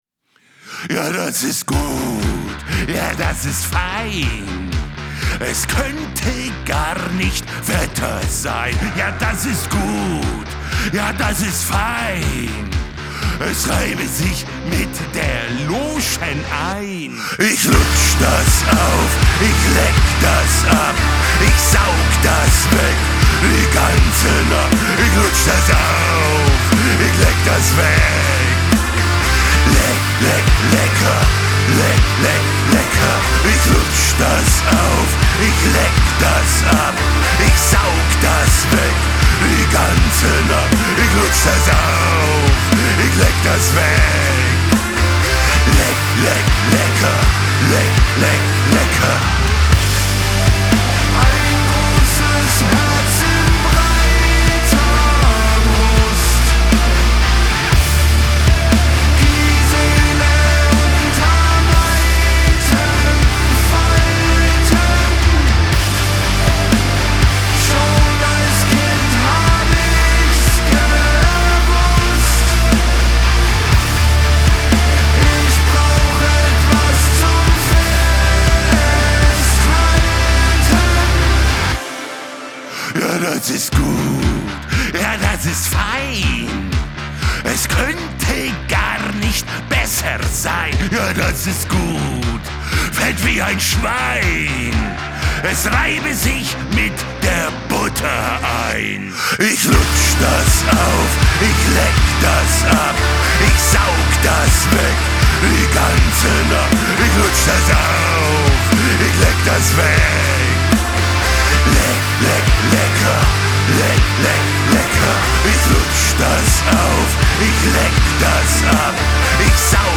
• Жанр: Metal